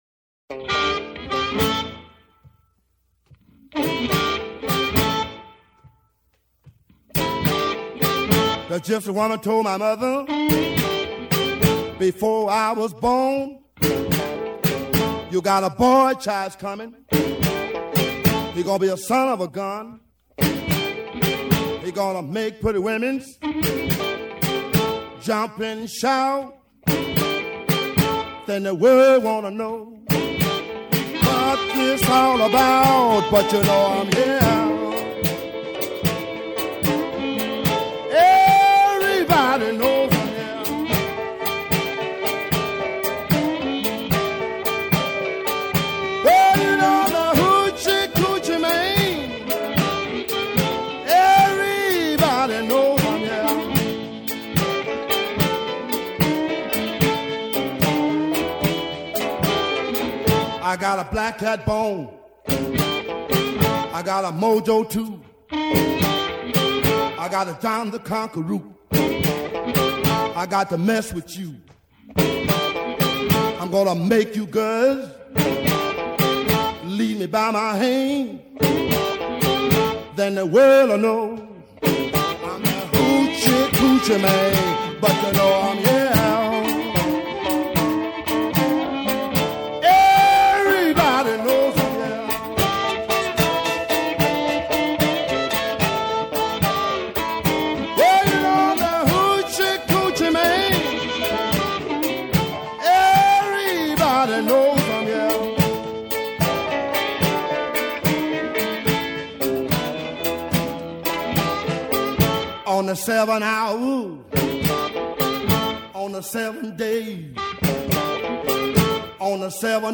Chicago Blues